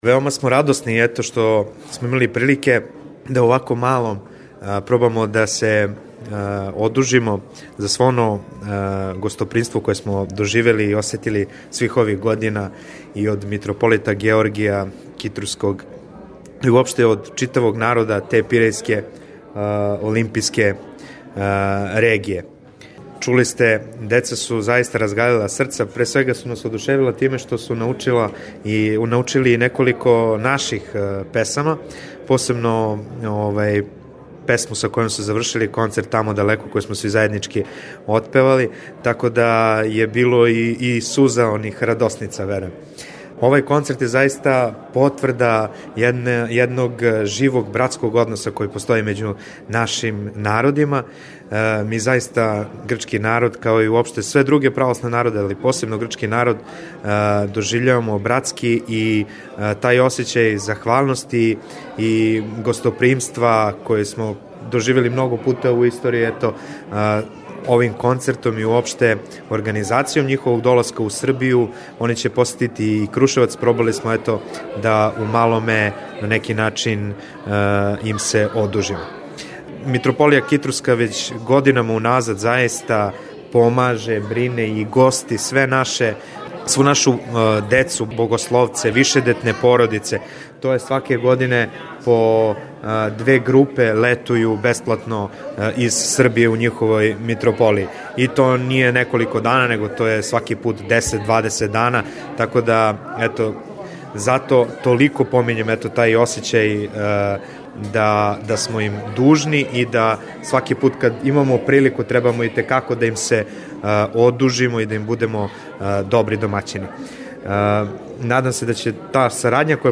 Изјаве за Радио Слово љубве